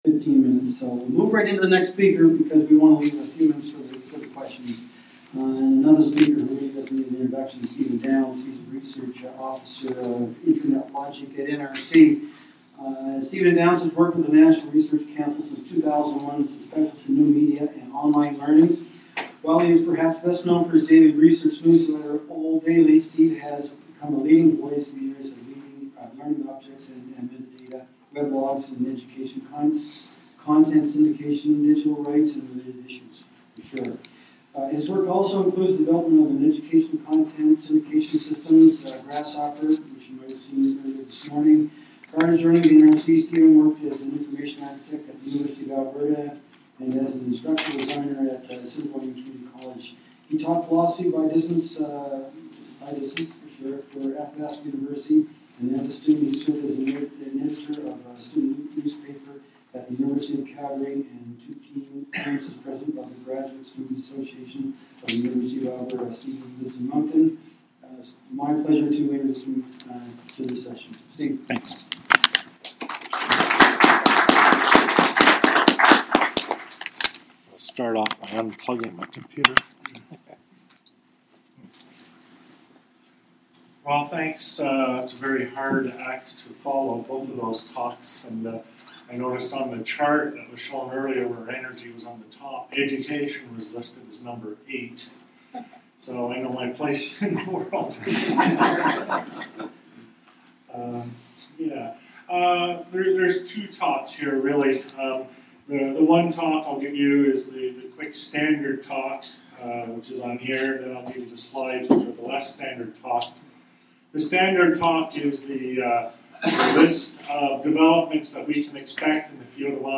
This short presentation during a panel is a summary of trends in advanced learning technologies.